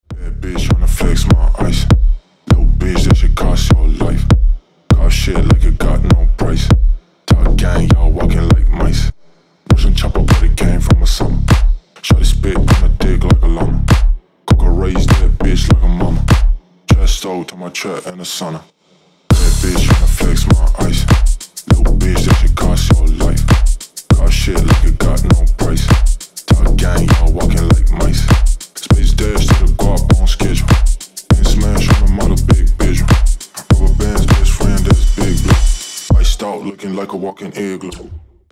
Рэп и Хип Хоп # Танцевальные
громкие